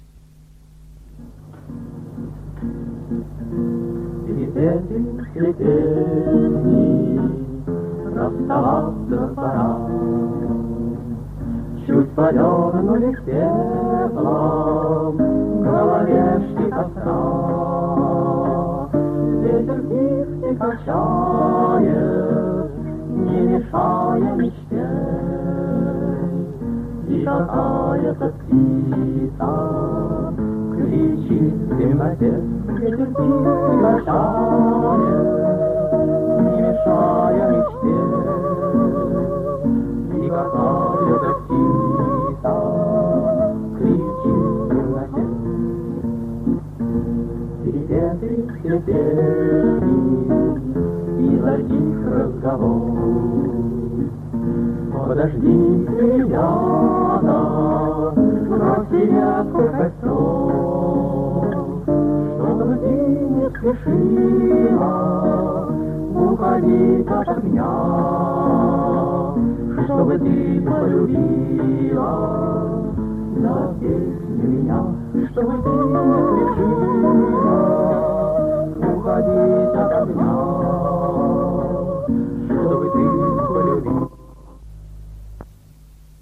Аудиозаписи Третьего Московского конкурса студенческой песни
ДК МЭИ. 7 декабря 1961 года.
Ансамбль МГРИ под гитару